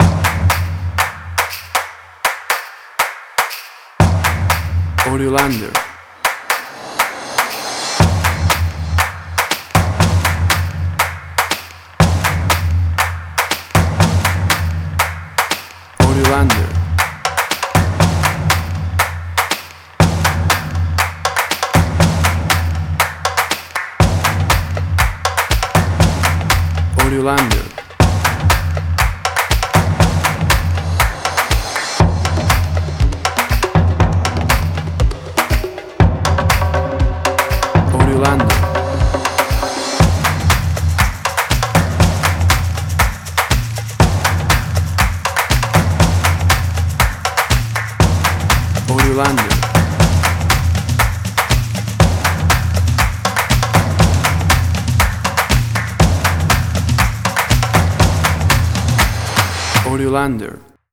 Tempo (BPM): 120